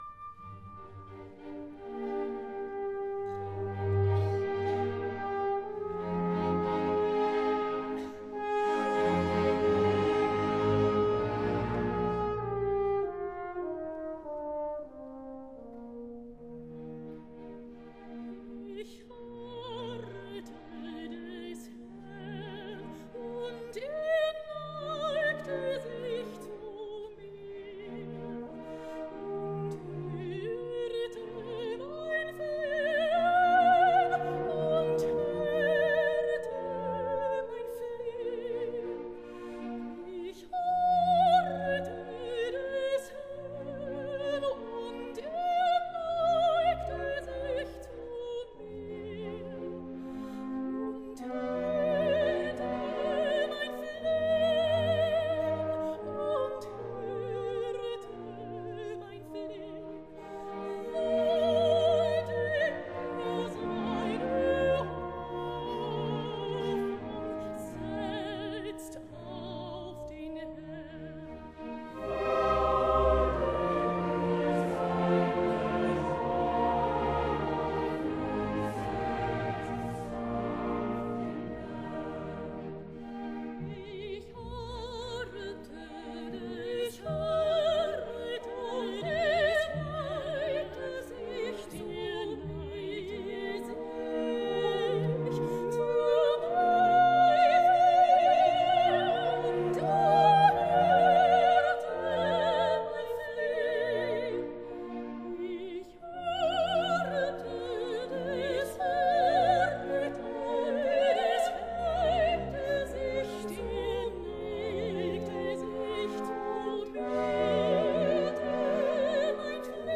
CD-Mitschnitt des Jubiläumskonzertes der Universität Leipzig,
Leipziger Universitätschor, Chor der Oper Leipzig
Mendelssohnorchester Leipzig